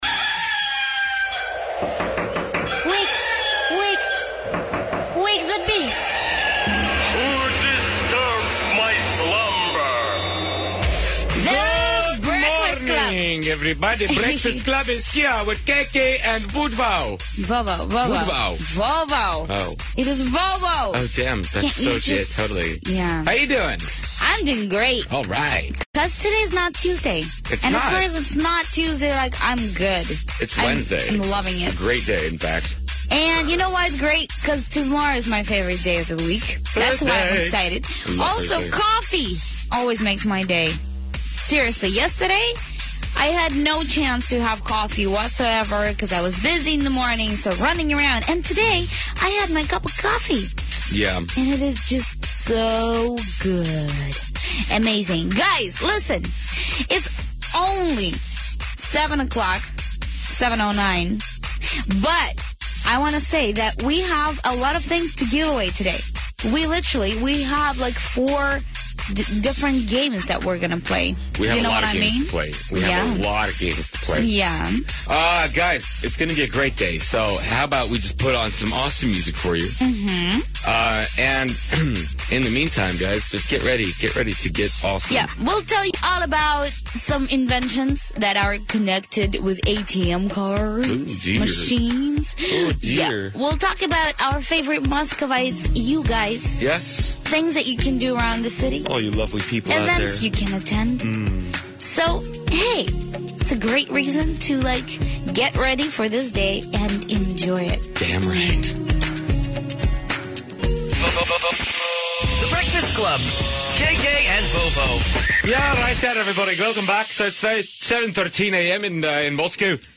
На Moscow FM звучит рок и говорят как можно прекрасно отдохнуть.
Moscow FM звучит смело и свежо.
Правда, стоит иметь в виду, что ведущие говорят на английском с русско-американским акцентом. На первый взгляд программы Moscow FM напоминают американские шоу, но стоит прислушаться к речи и понимаешь, что не все радиоведущие носители языка.